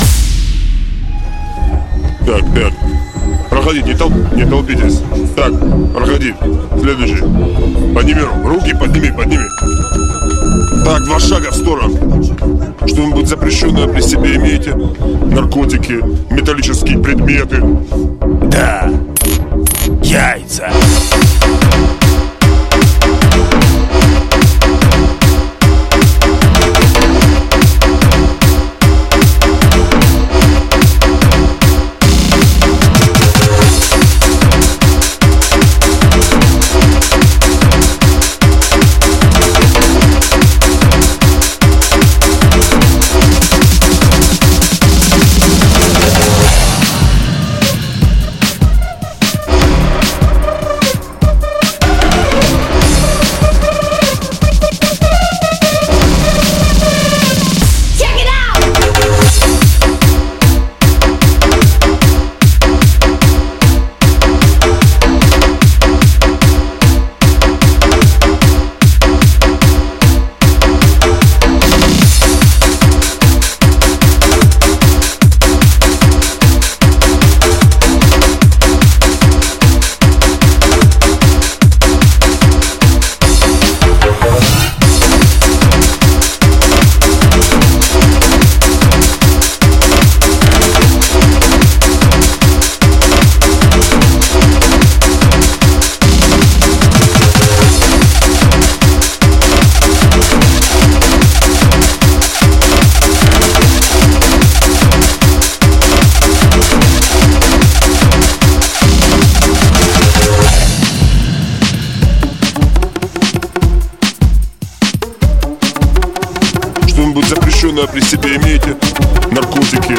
hard_bass___narezochka_z2_fm.mp3